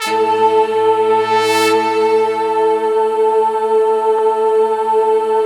SI1 BRASS08L.wav